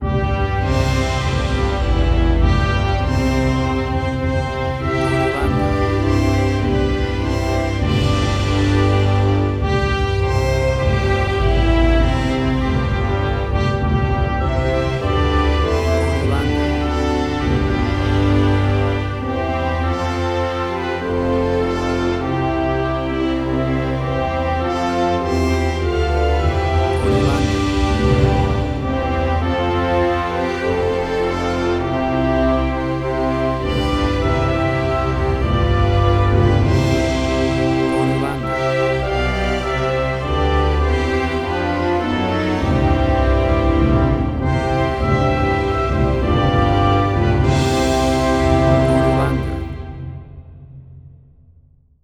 A vibrant and heartwarming traditional version
WAV Sample Rate: 16-Bit stereo, 44.1 kHz